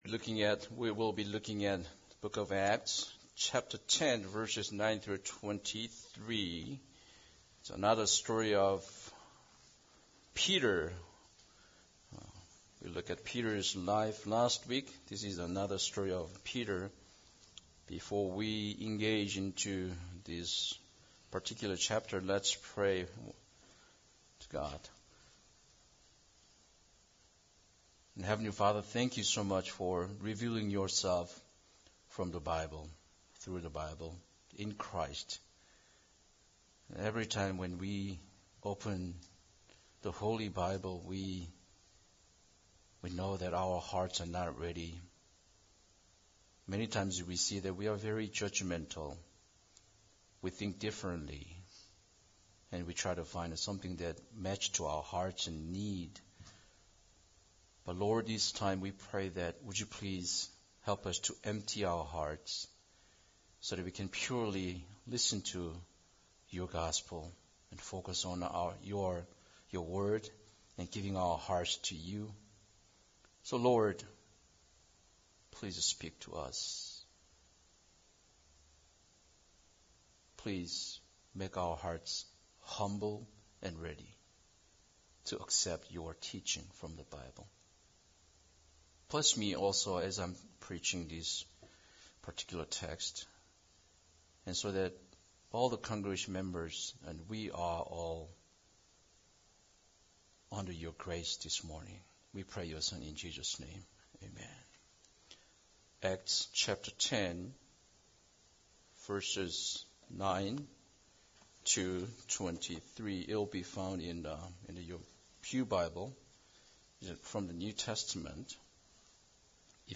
Acts 10:9-23 Service Type: Sunday Service Bible Text